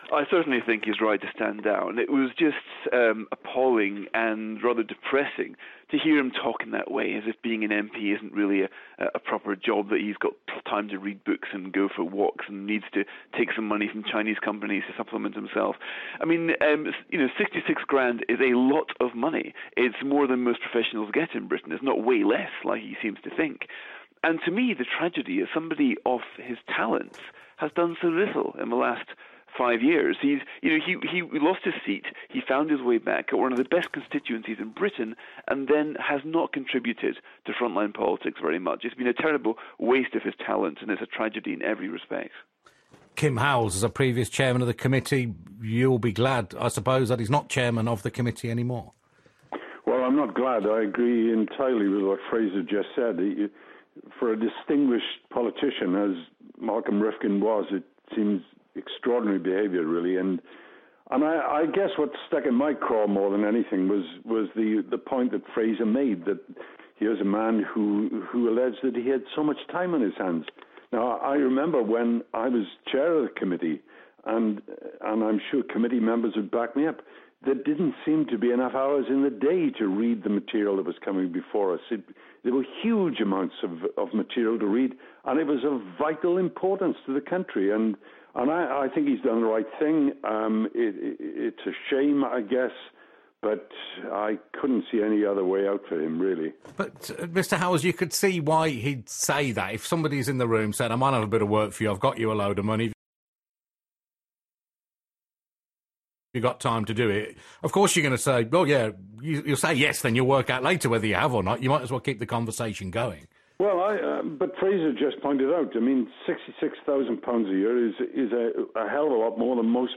Fraser Nelson on Radio 5 Live, 24 February 2015